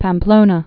(păm-plōnə, päm-plōnä)